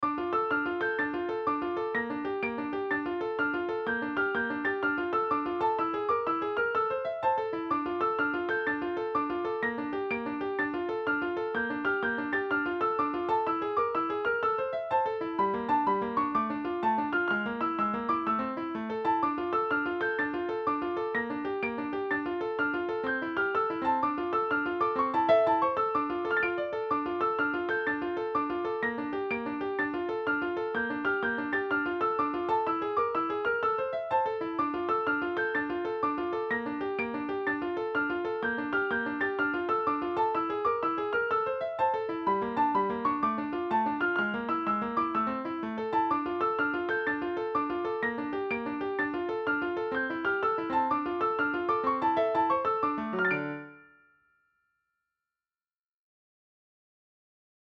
it is a mystery if it were in a minor key rather than major key